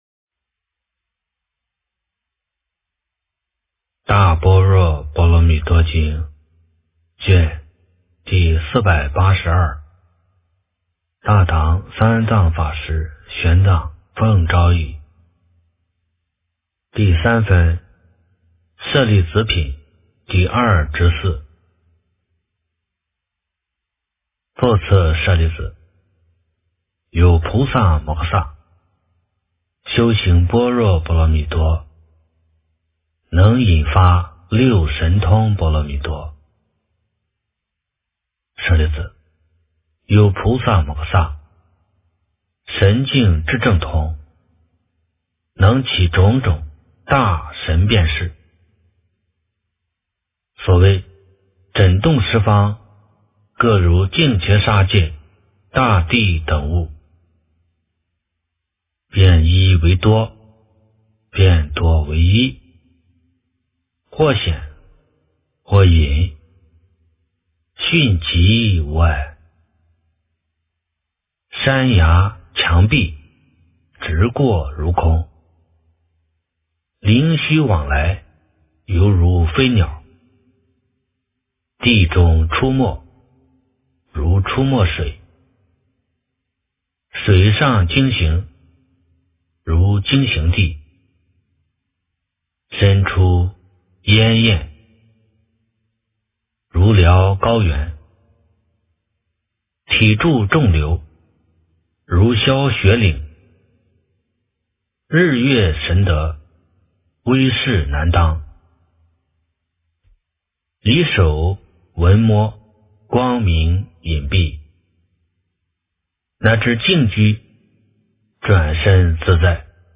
大般若波罗蜜多经第482卷 - 诵经 - 云佛论坛